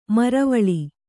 ♪ maruvāḷu